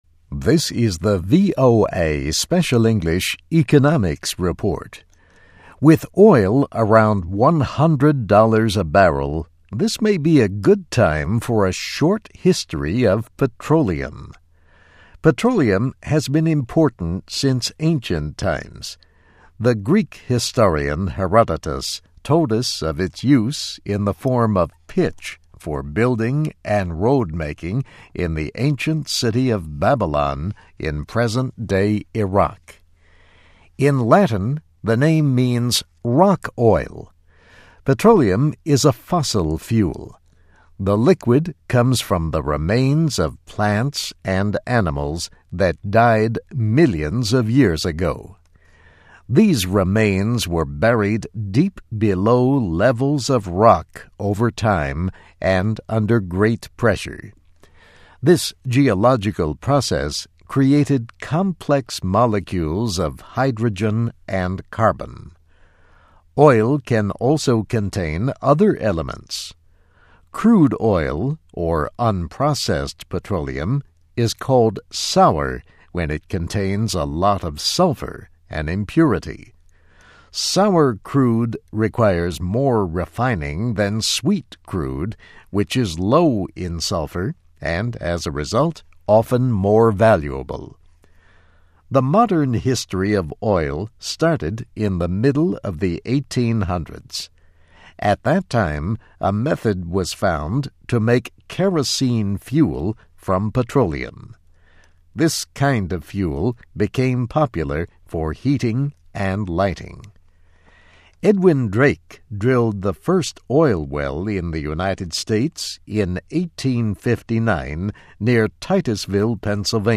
VOA Special English Economics Report - Petroleum: A Short History of Black Gold.